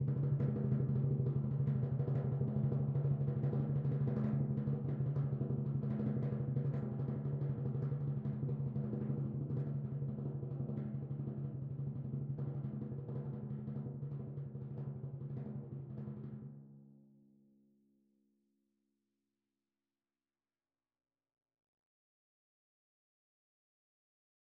Percussion / Timpani / Rolls
Timpani3_Roll_v3_rr1_Sum.wav